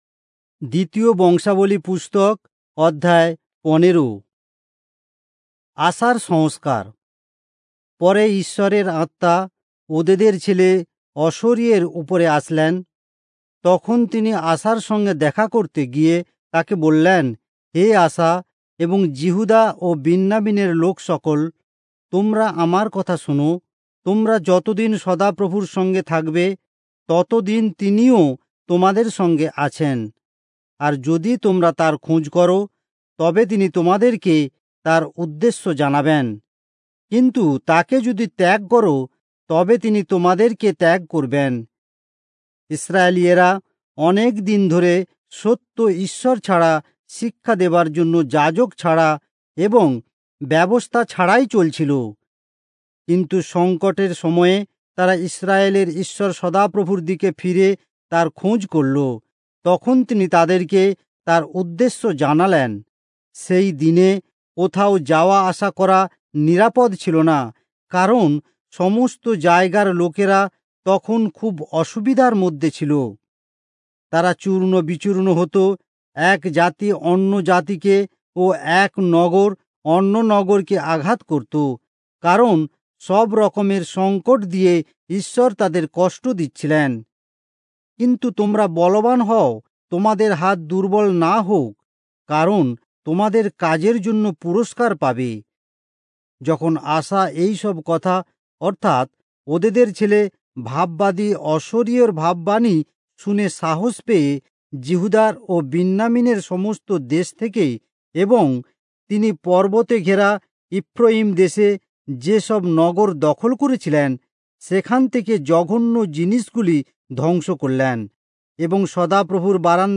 Bengali Audio Bible - 2-Chronicles 20 in Irvbn bible version